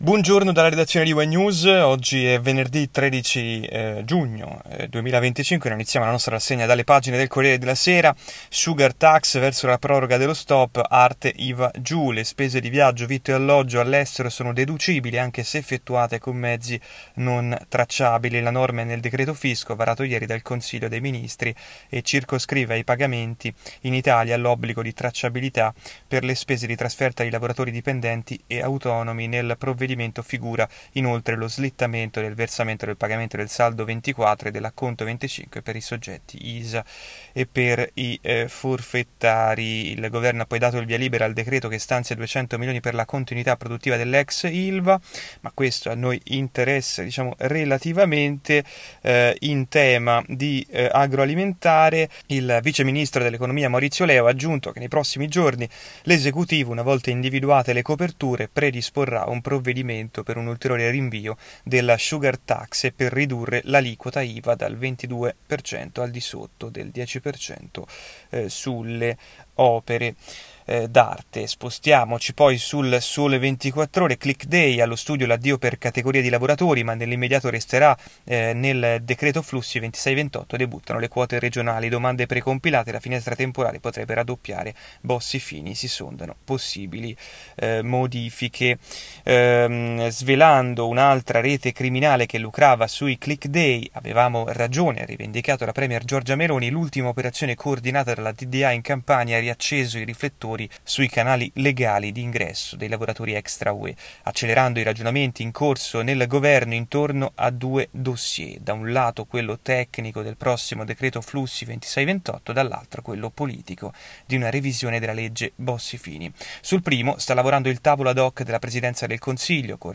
ไม่ต้องลงชื่อเข้าใช้หรือติดตั้ง Rassegna Stampa del 24 Aprile 2024.